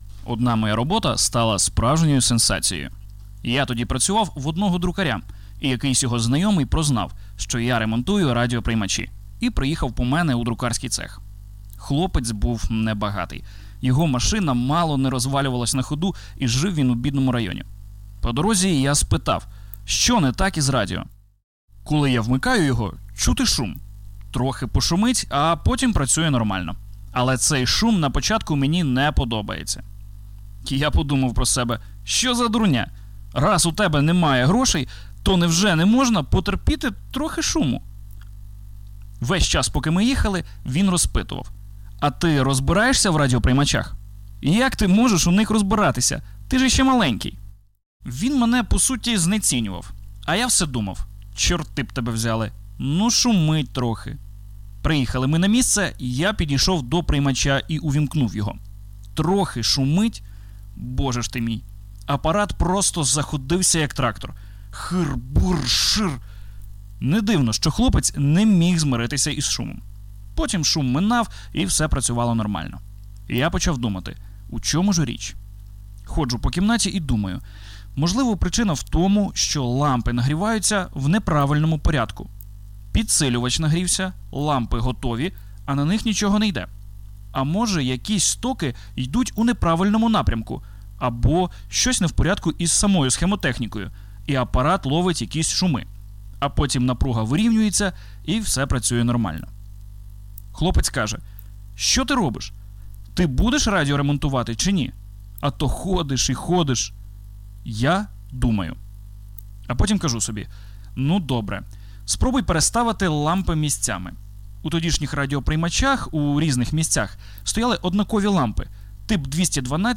Уривок Аудіокниги.